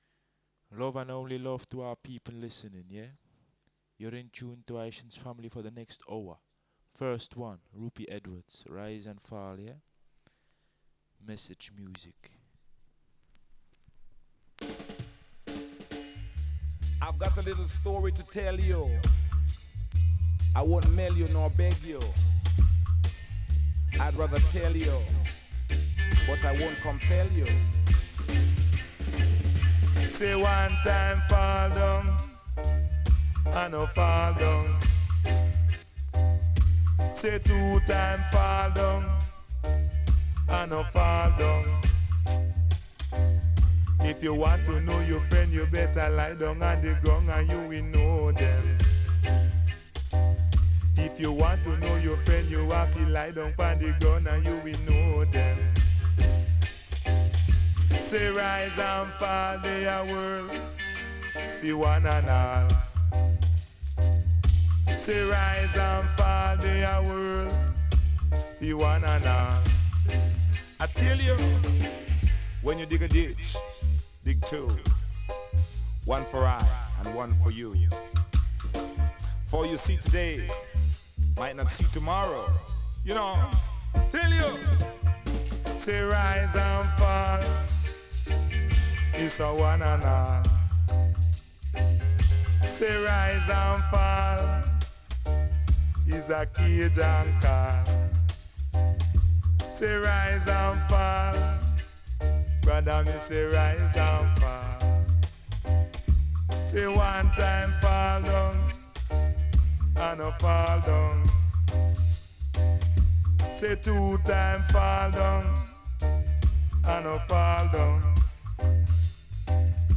From early Roots to deep Dub !